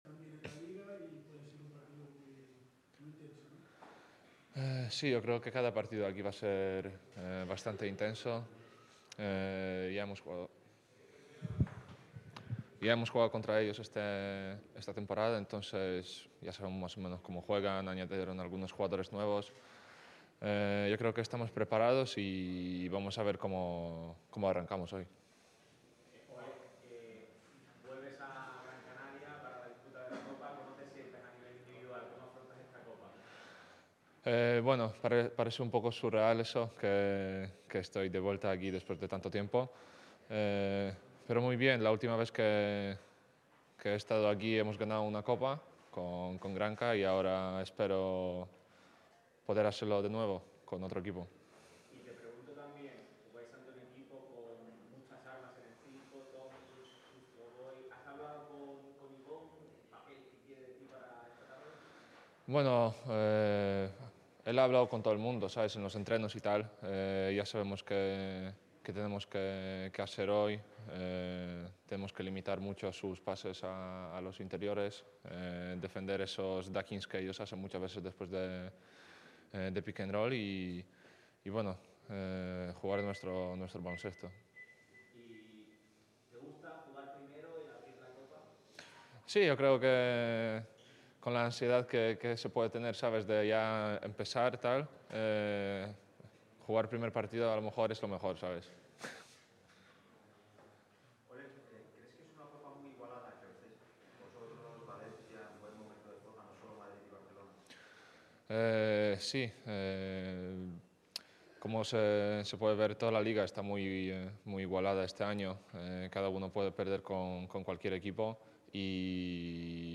El pívot polaco de Unicaja ha dedicado unos minutos a los medios antes del entrenamiento matutino. Olek Balcerowski ha repasado los puntos importantes coperos horas antes del pistoletazo de salida.